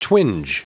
Prononciation du mot twinge en anglais (fichier audio)